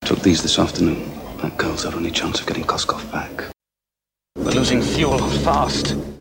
But he does seem to broaden BATH as Bond, at least in The Living Daylights (the key words are afternoon, chance and fast):
dalton_bond_bath.mp3